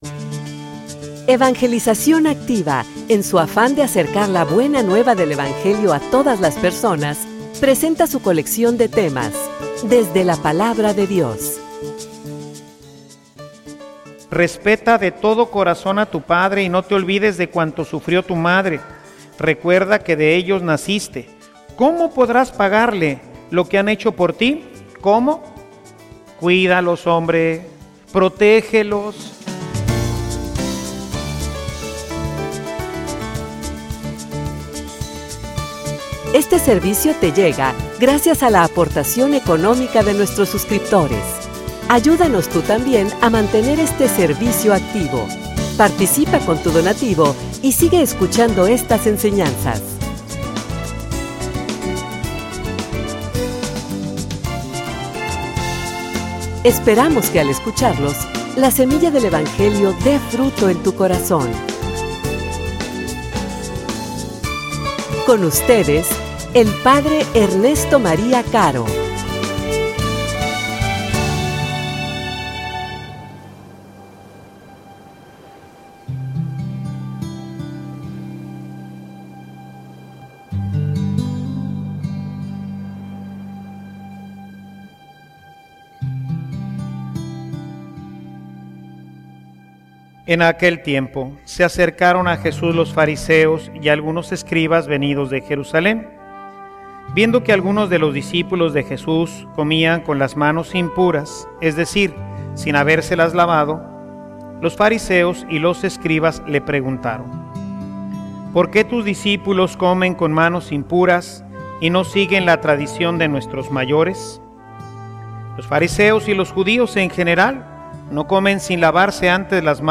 homilia_La_Ley.mp3